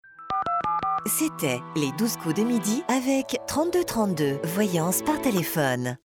Billboard TV et radio